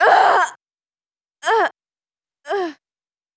dead.wav